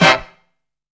CAT HIT.wav